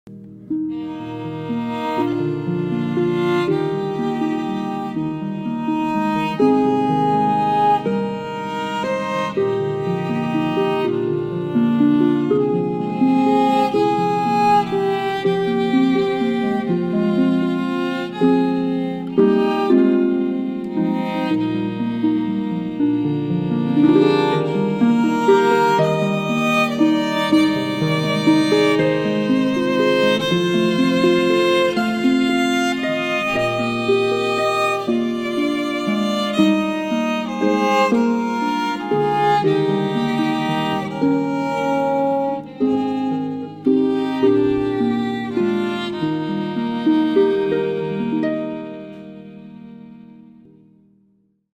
Ccb Hino 260 Violino Sound Effects Free Download